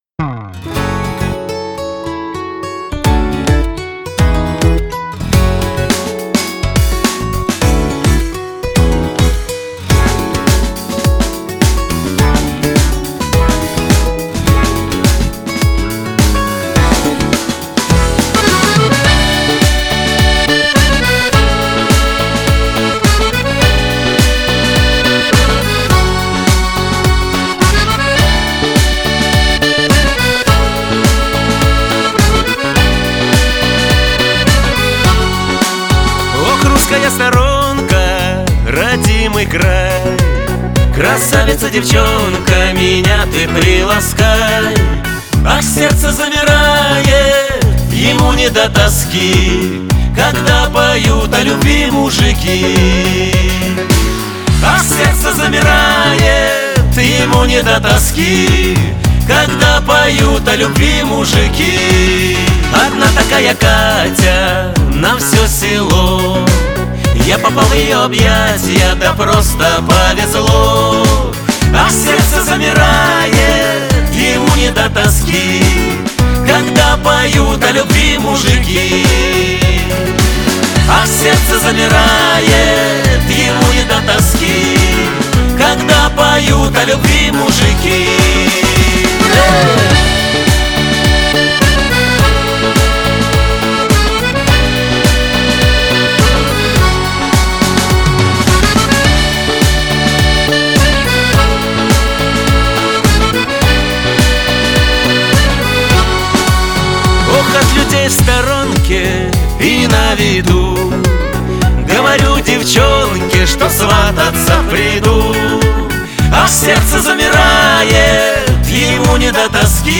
Хочу представить работу в которой вокал записан в WA-47.
Использовалась лампа Telefunken ECC83